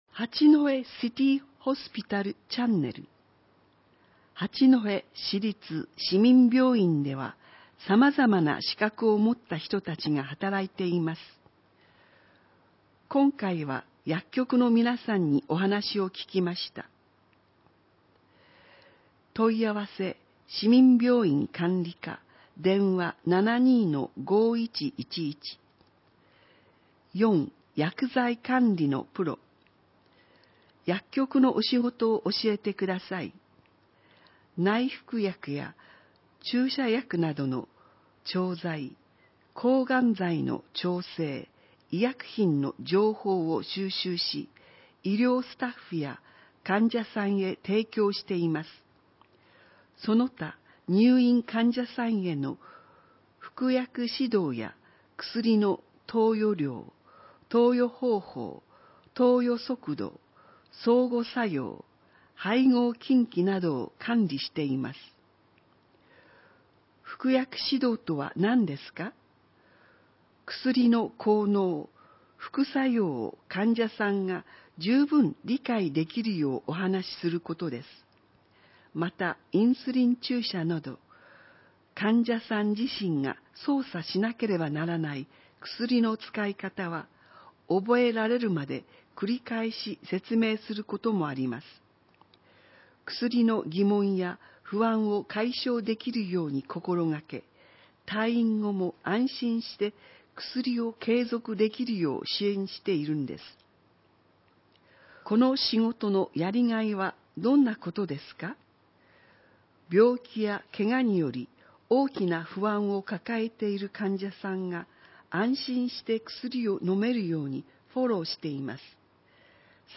音声は、ボランティアグループ「やまびこの会」が朗読録音したものです。